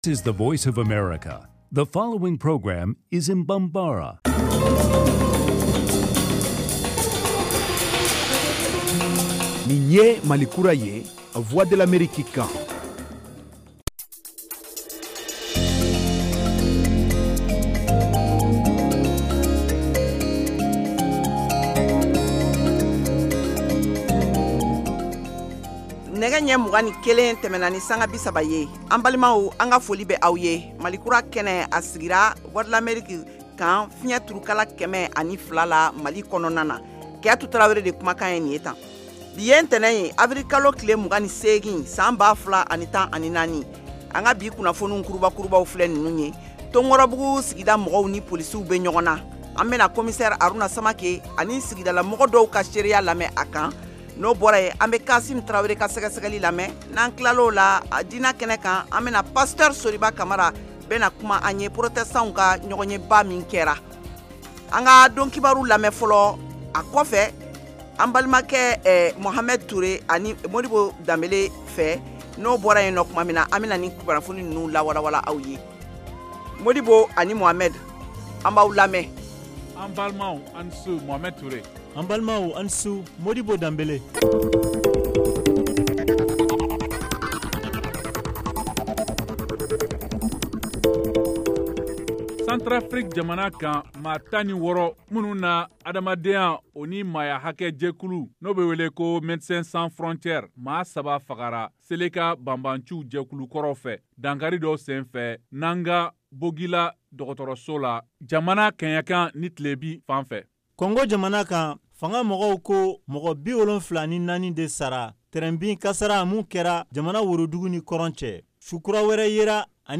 en direct de Washington. Au menu : les nouvelles du Mali, les analyses, le sport et de l’humour.